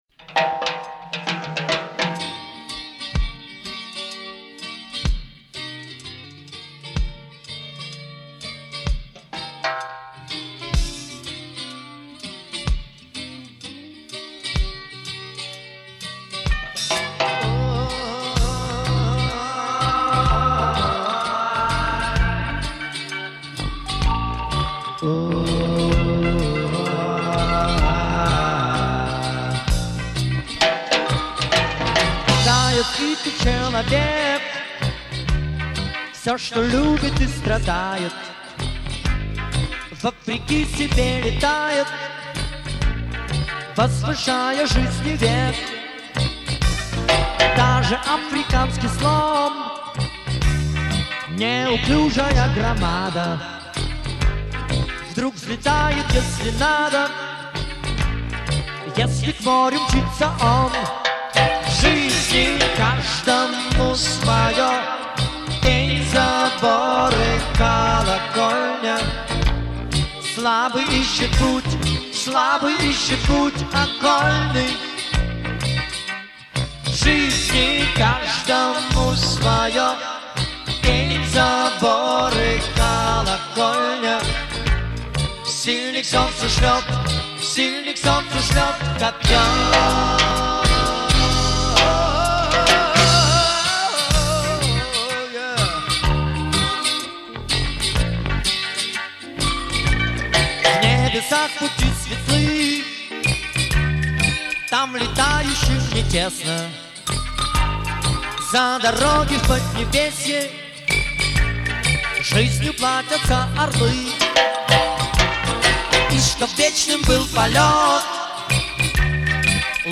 рок-группа